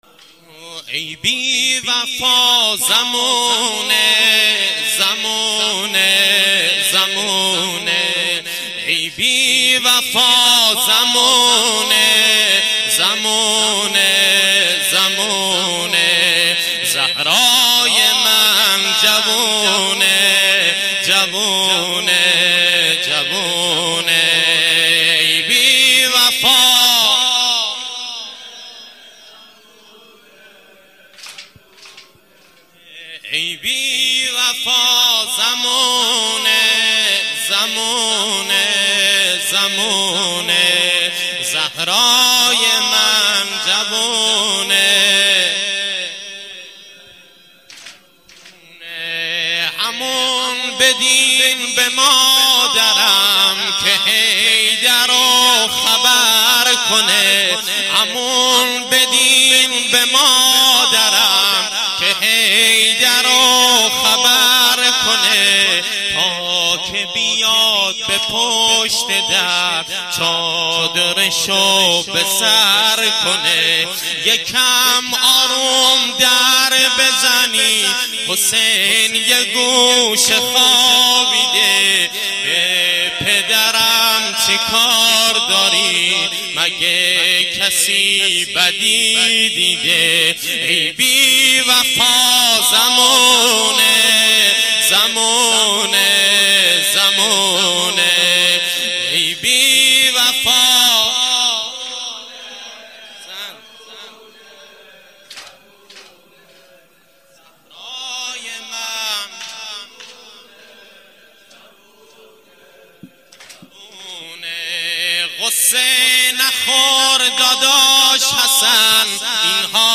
واحد - ای بی وفا زمونه - مداح